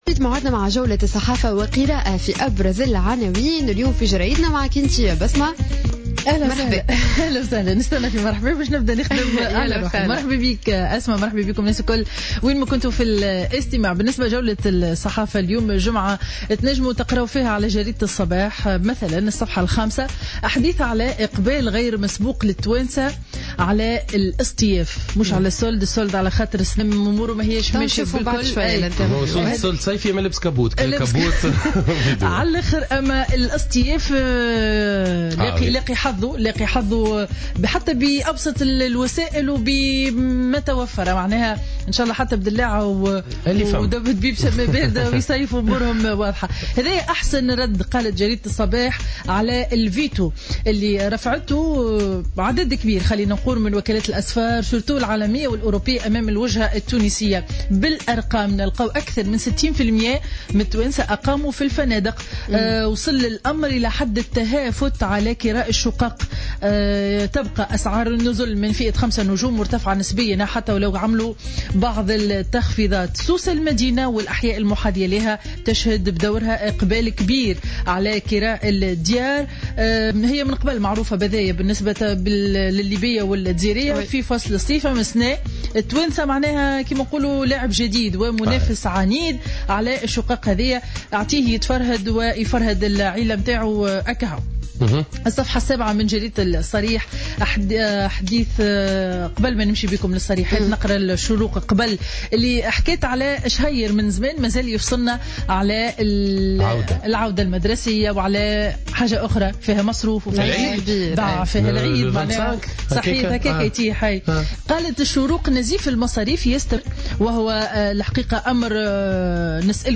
Revue de presse du vendredi 14 août 2015